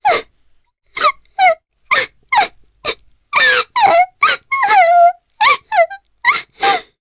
Ophelia-Vox_Sad_jp.wav